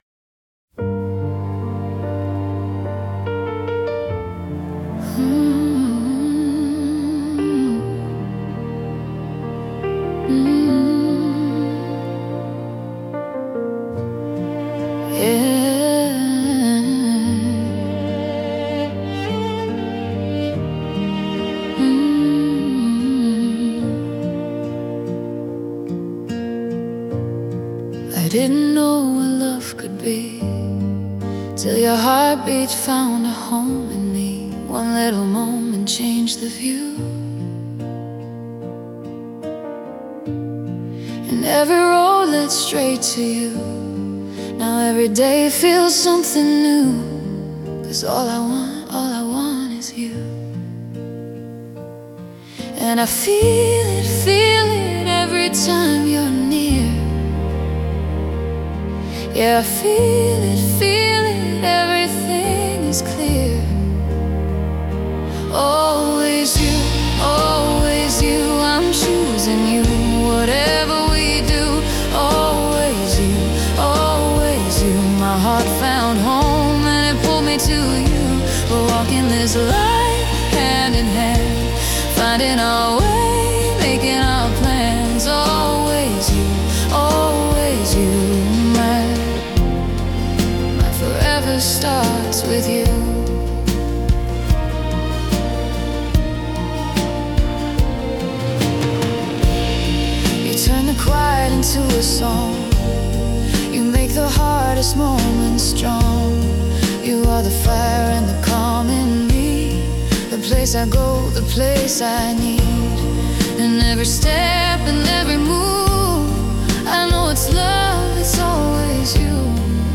Für euch: Lovesong „Always YOU!“
Manche Momente brauchen einen großen Schuss Liebe und Romantik.
Musik & Produktion: Suno AI